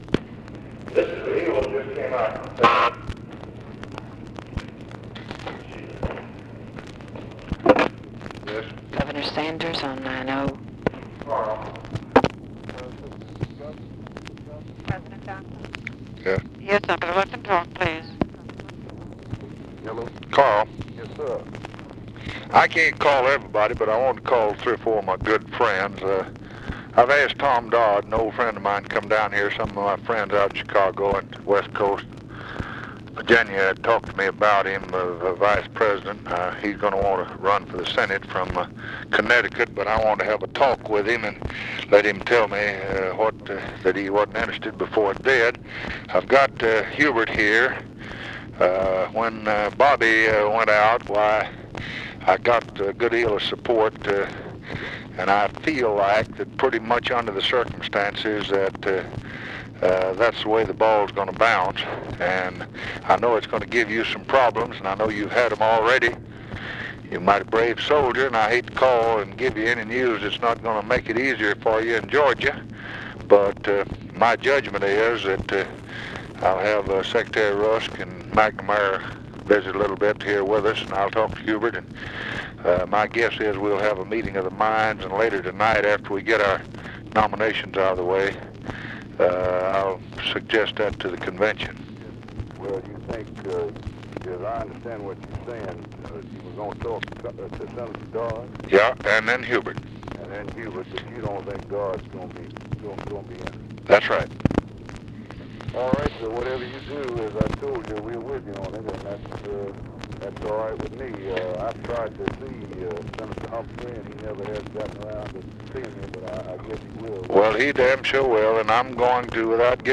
Conversation with CARL SANDERS, August 26, 1964
Secret White House Tapes